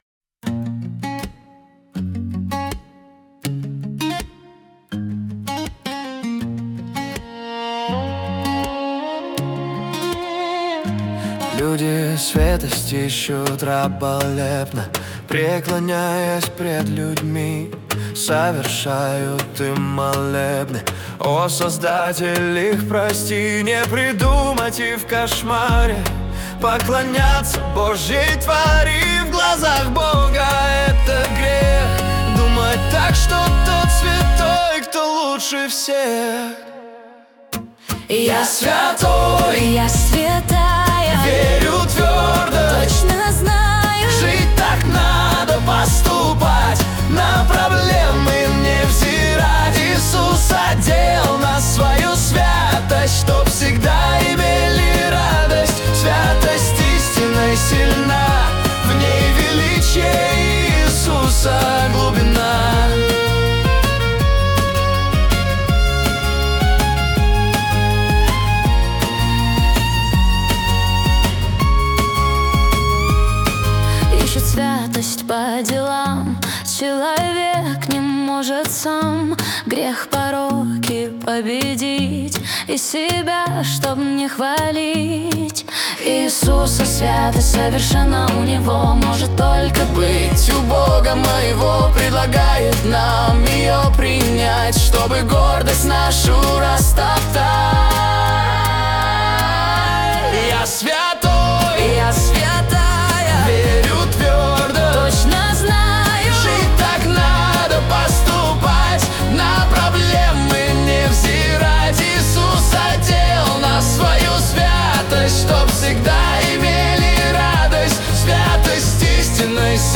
песня ai
229 просмотров 785 прослушиваний 85 скачиваний BPM: 162